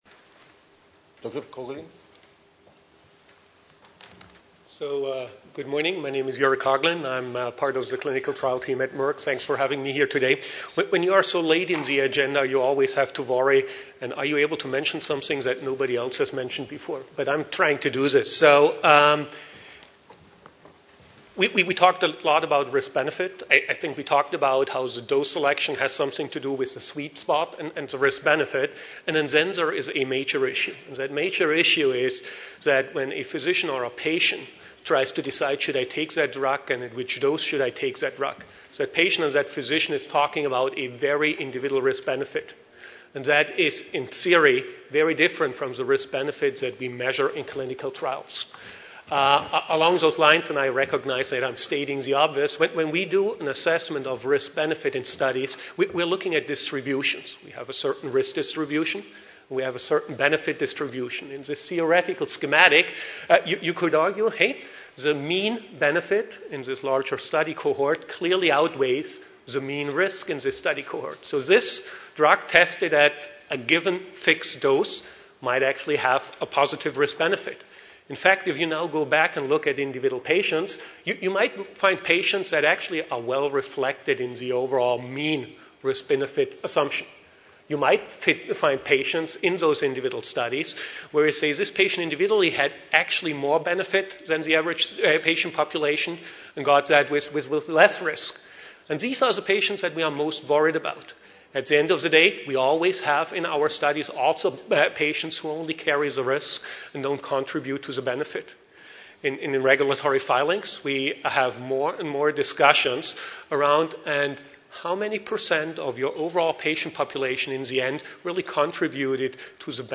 Cardiovascular Clinical Trialists (CVCT) Forum – Paris 2012